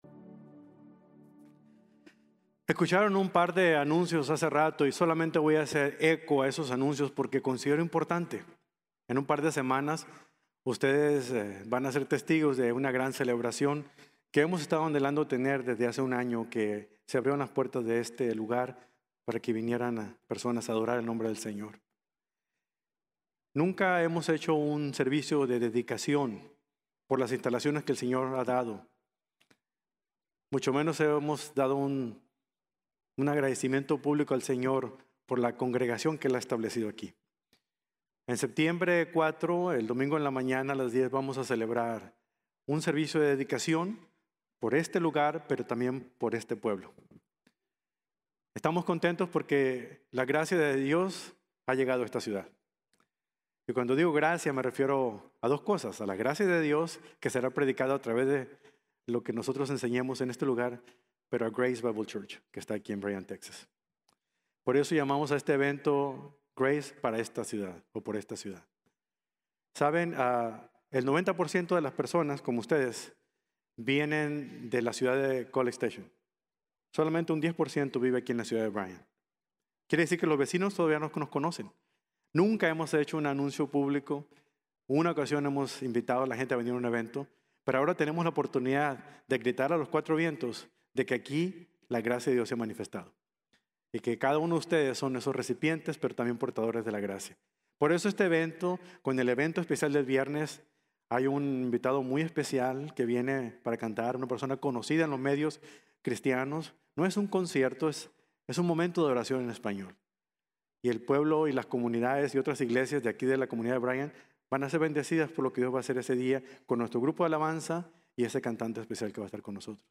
ESCATOLOGIA: La Doctrina del Futuro | Sermón | Grace Bible Church